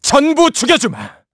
Riheet-Vox_Skill5_kr-02.wav